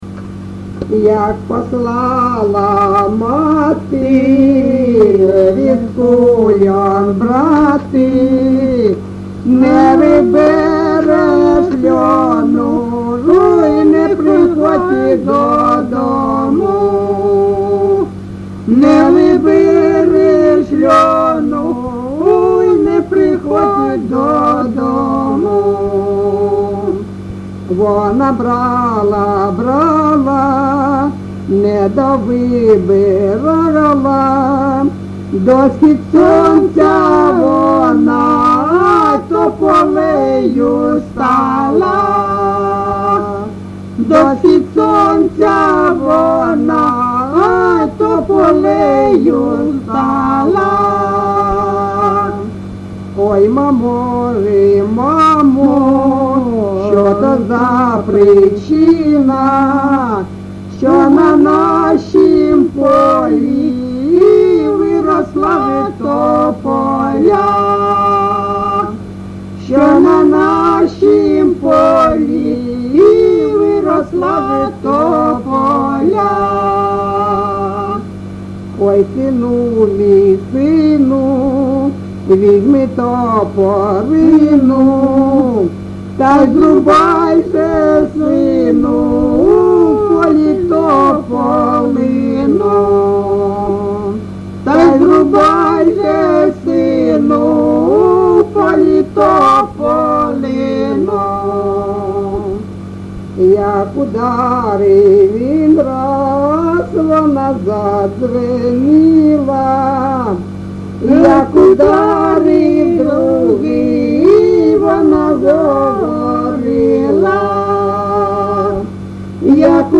ЖанрПісні з особистого та родинного життя, Балади
Місце записус. Калинове Костянтинівський (Краматорський) район, Донецька обл., Україна, Слобожанщина